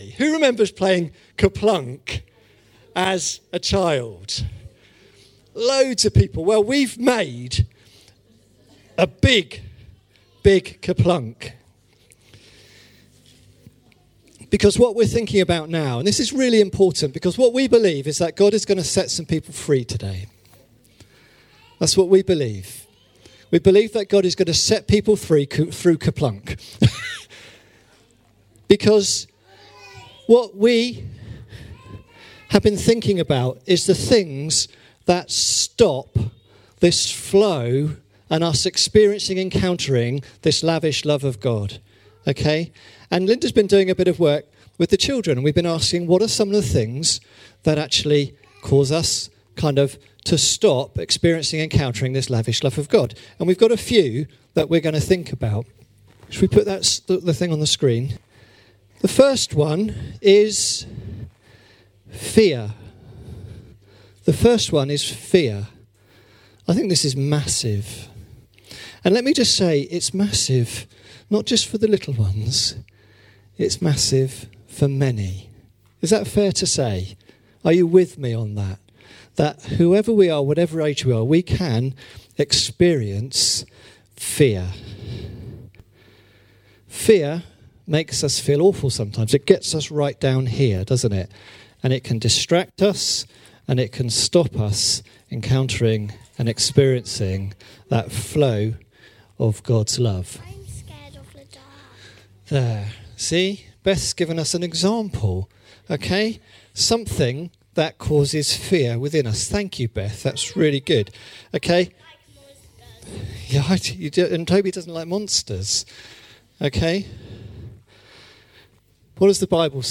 5 March 2017 family service and communion
5-March-2017-family-service-and-communion.mp3